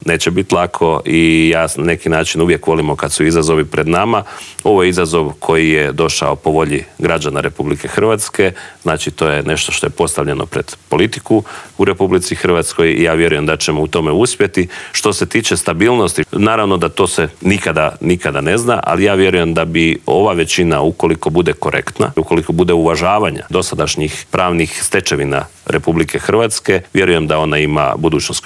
ZAGREB - Nakon što je predsjednik HDZ-a Andrej Plenković predao 78 potpisa i od predsjednika Zorana Milanovića dobio mandat da treći put zaredom sastavi Vladu, saborski zastupnik češke i slovačke nacionalne manjine Vladimir Bilek otkrio je u Intervjuu tjedna Media servisa tko je od manjinaca dao svoj potpis.